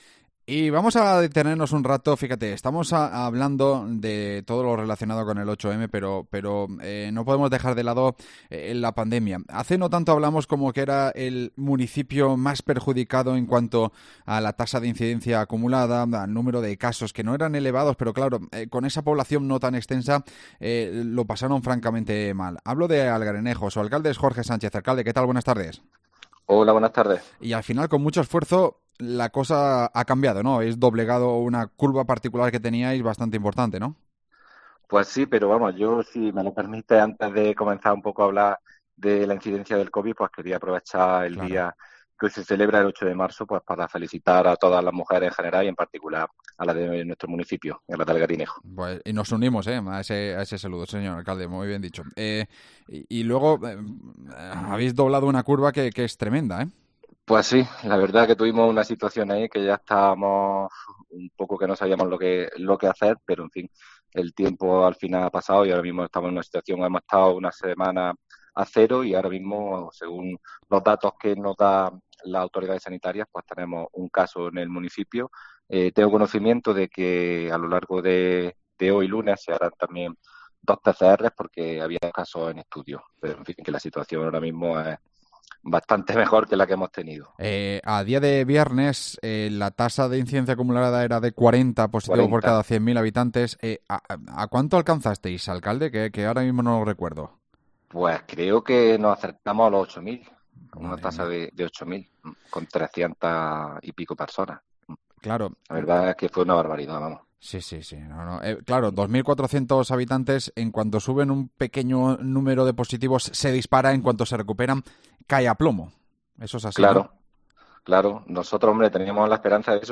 AUDIO: Lo cuenta su alcalde Jorge Sánchez